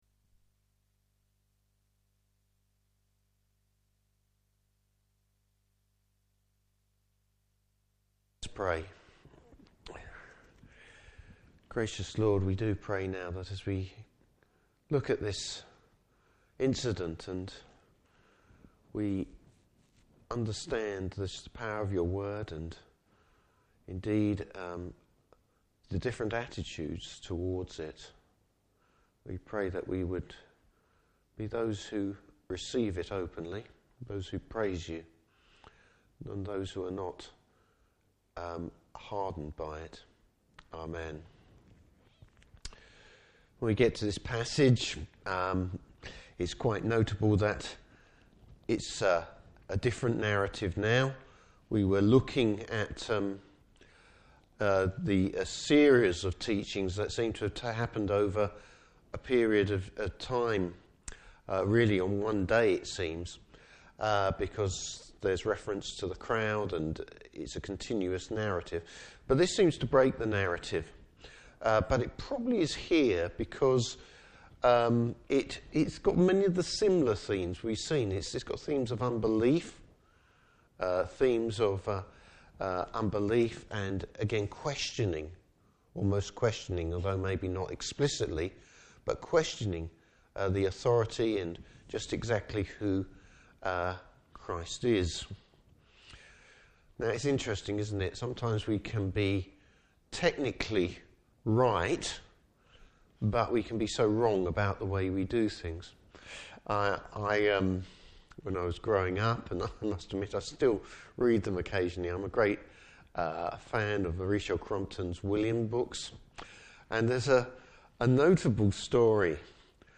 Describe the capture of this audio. Service Type: Morning Service Bible Text: Luke 13:10-17.